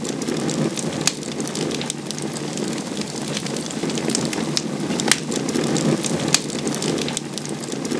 molotov_burn.wav